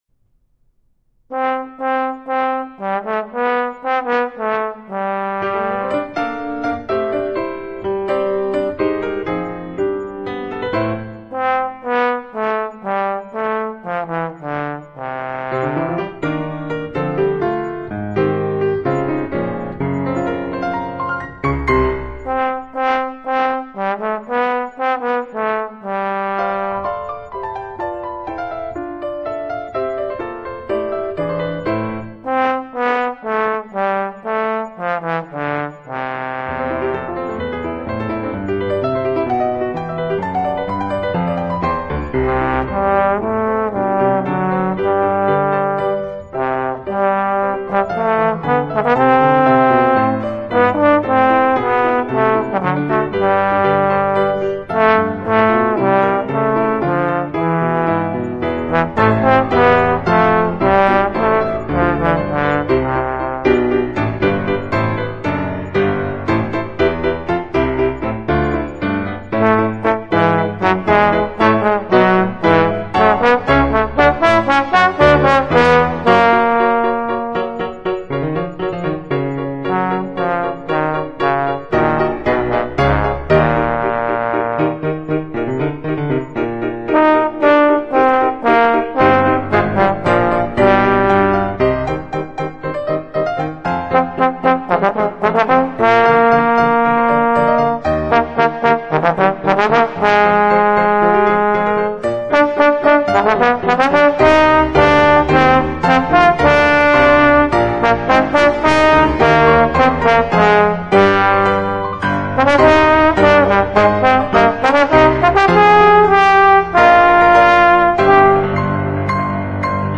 Voicing: Trombone and Piano